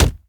punch2.ogg